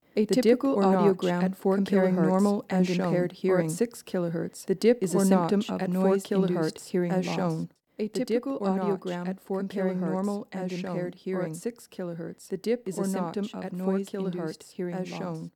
• Listen to the following audio file of two women speaking at the same time. The audio will play twice - once with both voices coming out of both speakers and the second time with one voice coming out of each speaker separately.
Cocktail_Party_Effect.wav